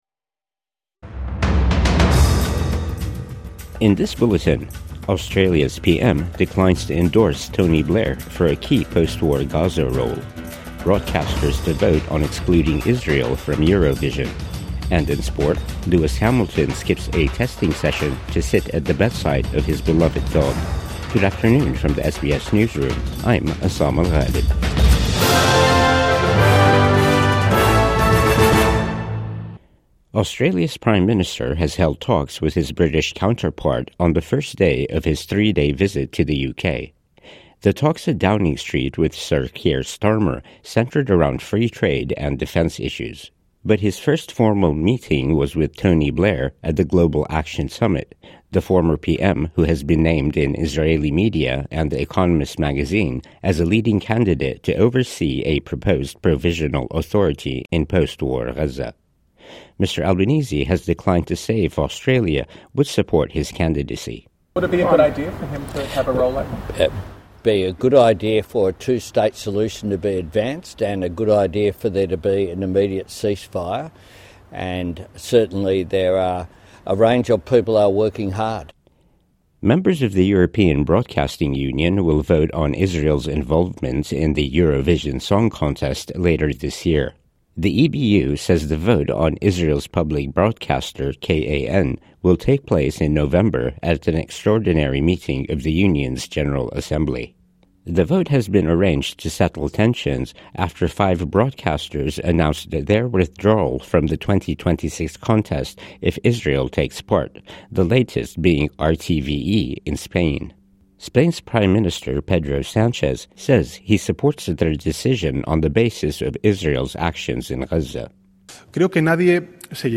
Midday News Bulletin 27 September 2025